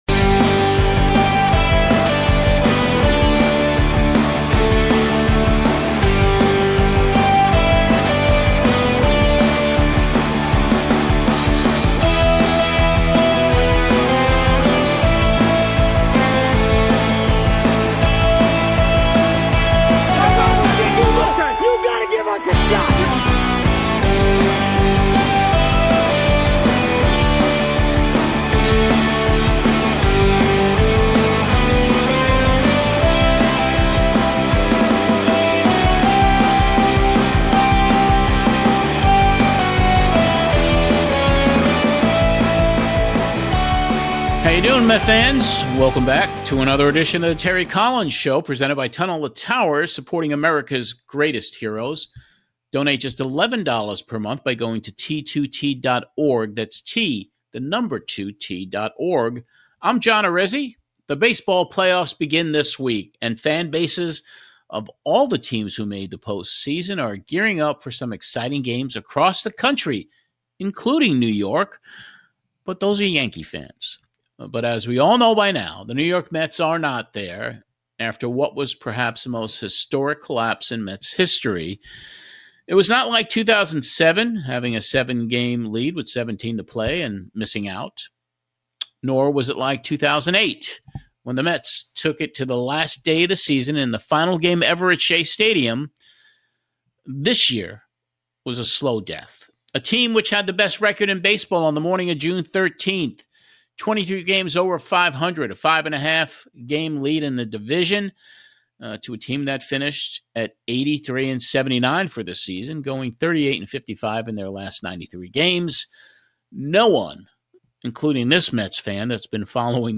Then to remember some better times, Terry Collins and Radio Voice of the NY Mets, Howie Rose share stories and hear Howie's memorable calls and highlights from the 2015 playoffs, which saw the Mets capture the NL Championship.